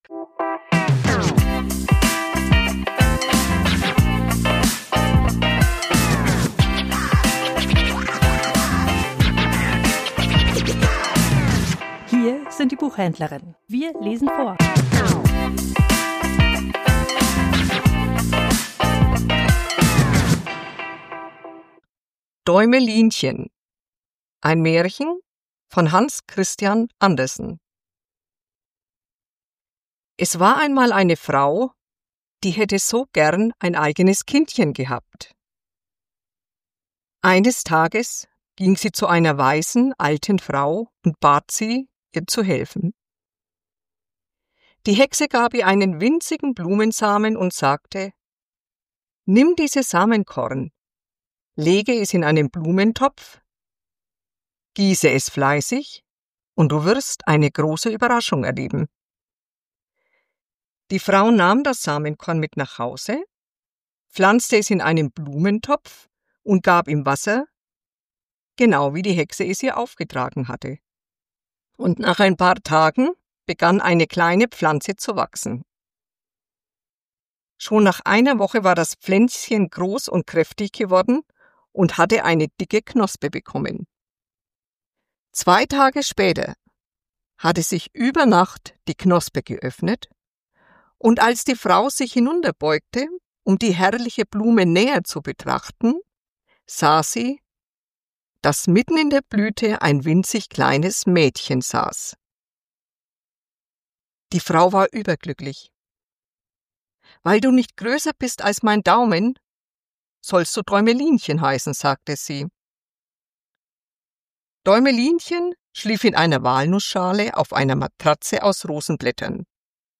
Vorgelesen: Däumelinchen ~ Die Buchhändlerinnen Podcast